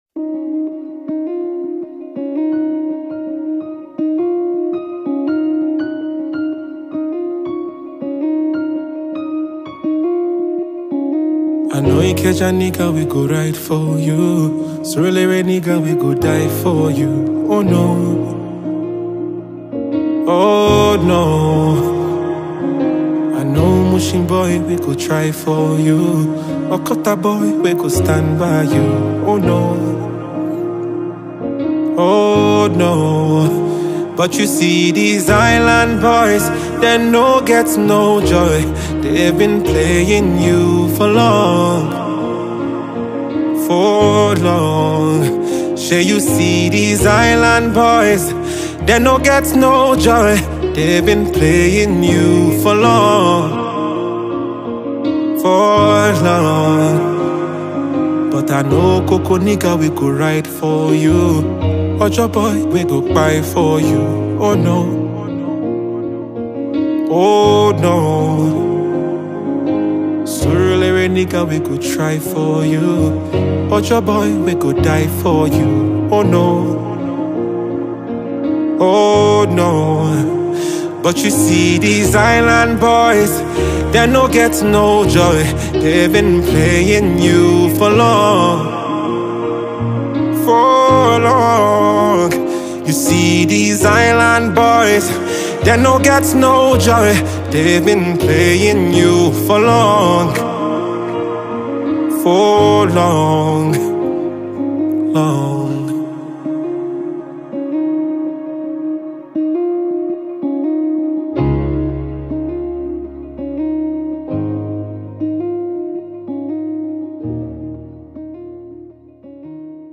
Afro-soul
Known for his signature blend of smooth vocals